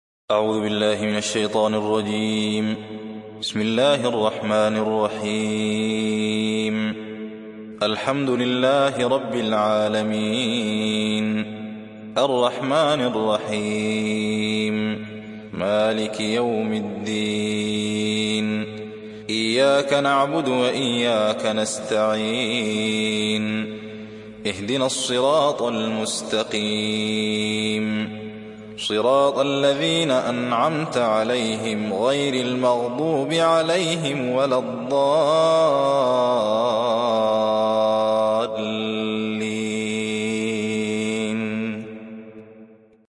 تحميل سورة الفاتحة mp3 بصوت الزين محمد أحمد برواية حفص عن عاصم, تحميل استماع القرآن الكريم على الجوال mp3 كاملا بروابط مباشرة وسريعة